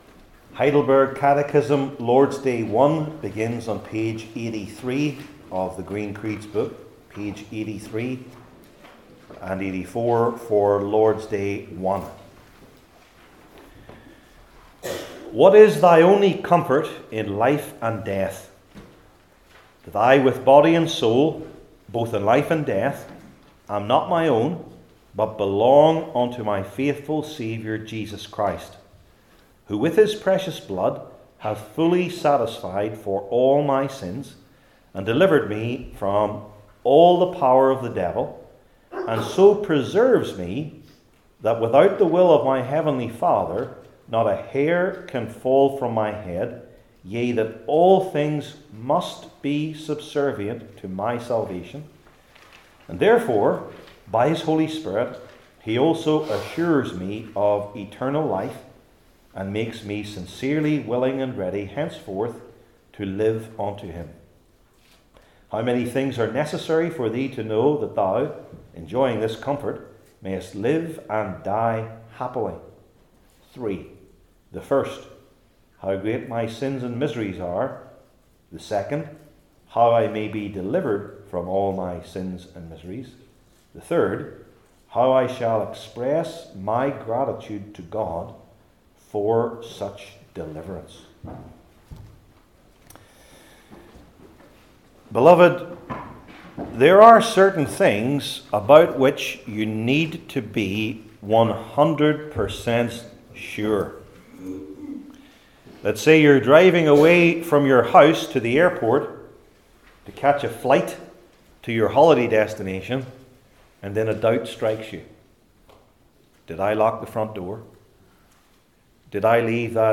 I John 5 Service Type: Old Testament Sermon Series I. Regarding Jesus Christ II.